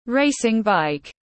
Xe đạp đua tiếng anh gọi là racing bike, phiên âm tiếng anh đọc là /ˈreɪ.sɪŋ ˌbaɪk/ .
Racing bike /ˈreɪ.sɪŋ ˌbaɪk/